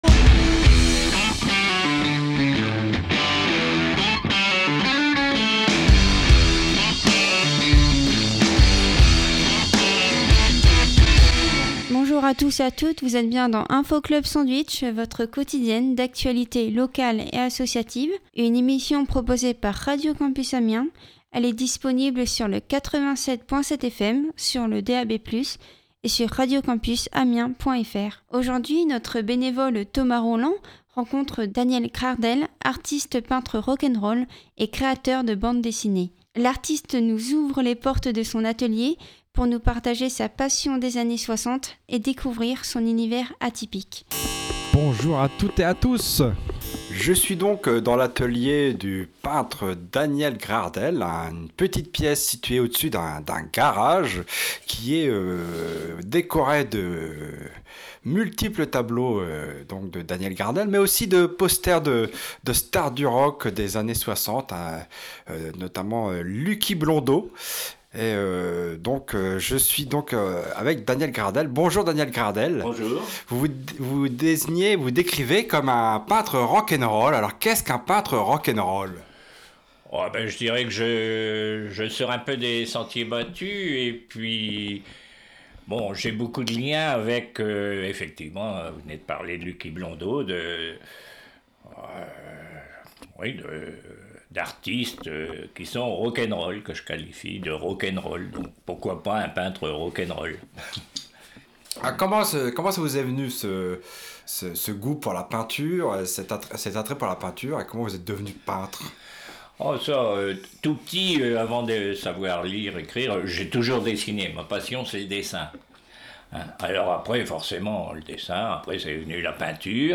Radio Campus Amiens est allé visiter son atelier d’où il évoque sa peinture, ses influences, ses techniques de travail et aussi son amour des femmes et de la musique.